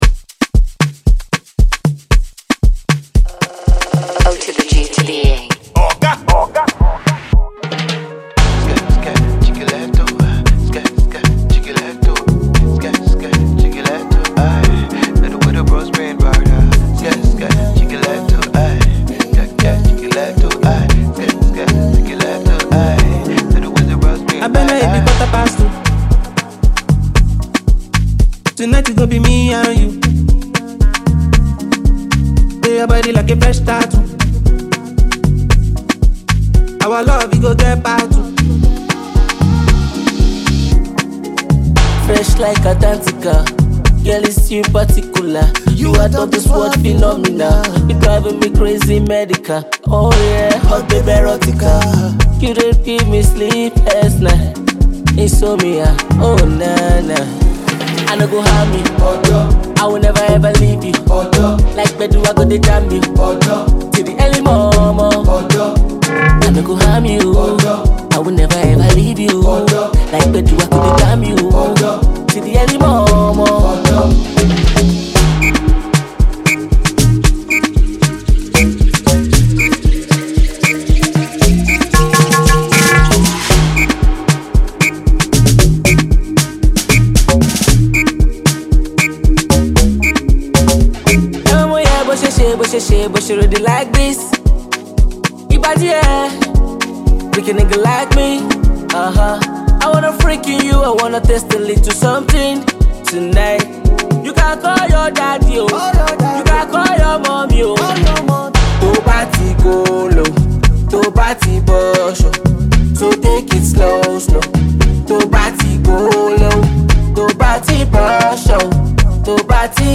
a dynamic and infectious track
creating a rich, textured sound that is fresh and familiar.